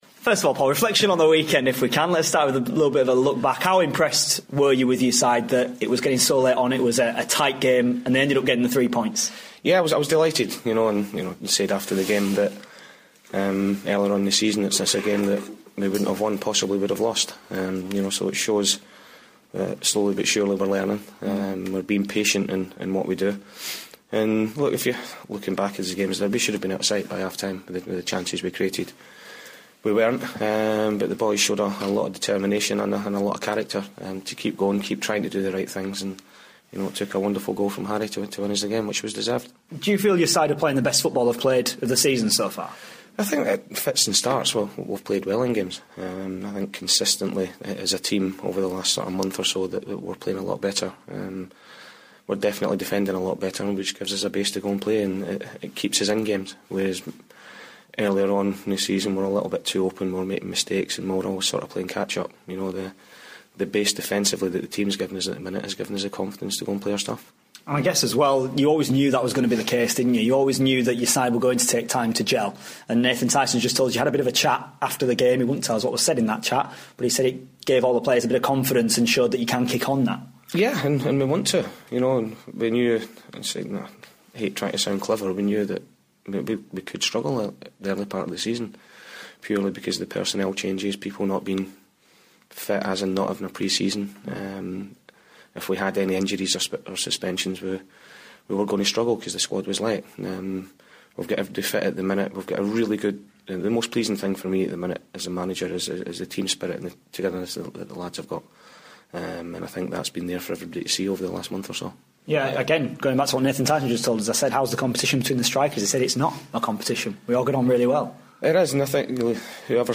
INTERVIEW: Doncaster Rovers manager Paul Dickov ahead of their game with Notts County